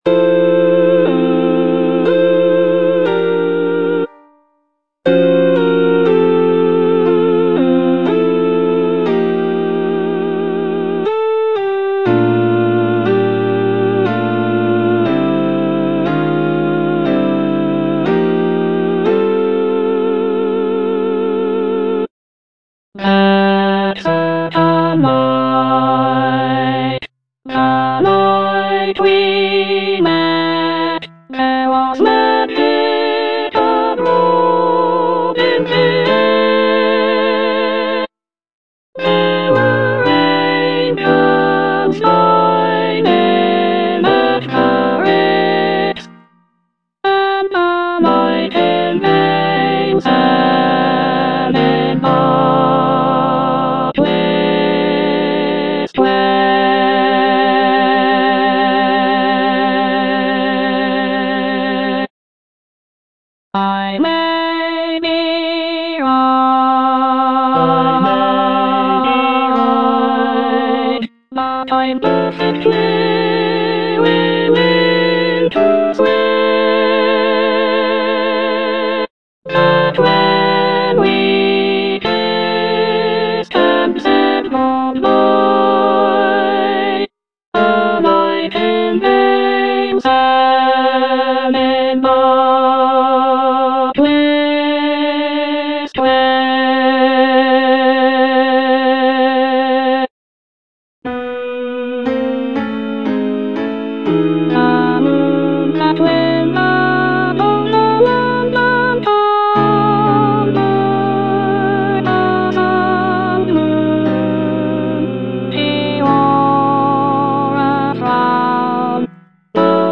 Soprano (Emphasised voice and other voices)
lush harmonies and intricate vocal arrangements